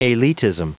Prononciation du mot : elitism